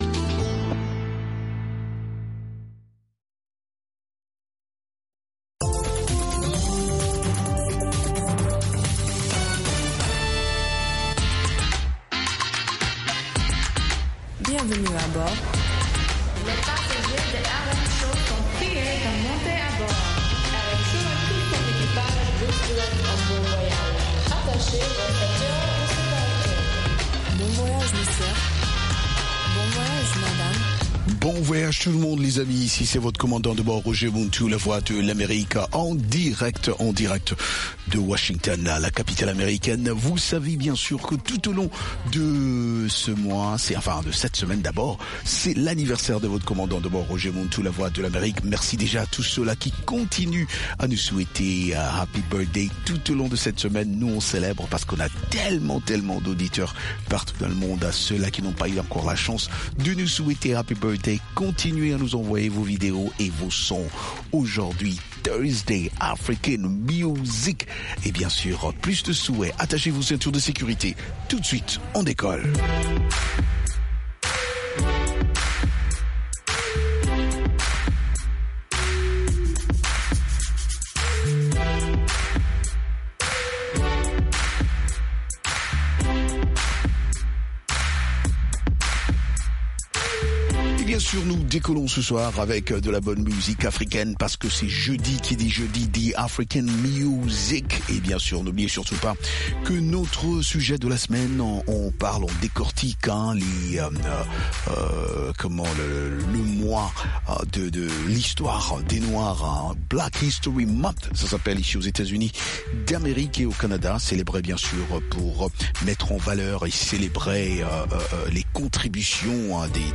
des reportages et interviews sur des événements et spectacles africains aux USA ou en Afrique.